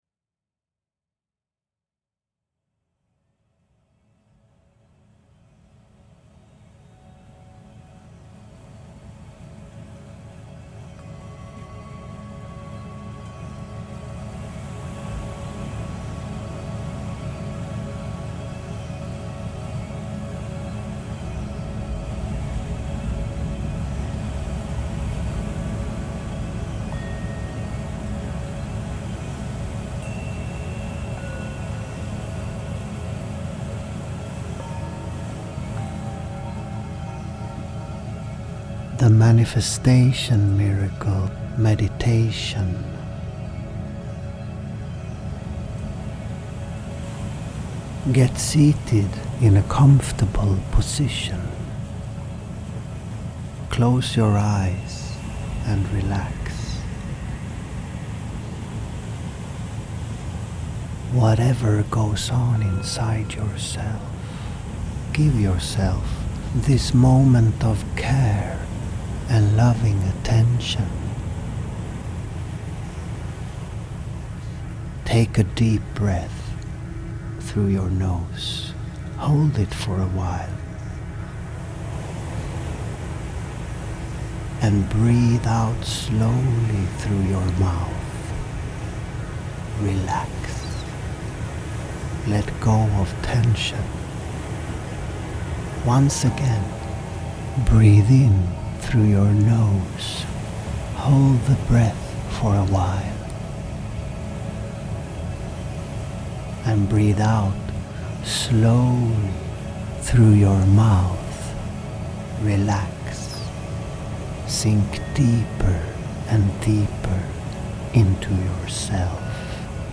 Click Here to Download Your Meditation Now For Best Results, Use Headphones Or Earplugs.